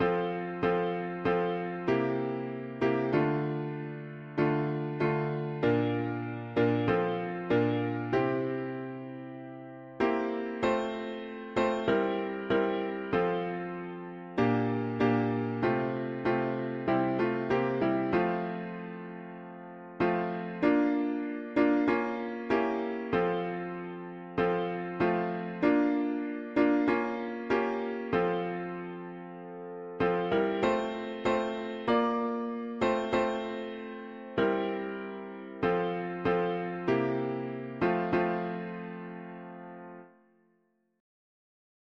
Key: F major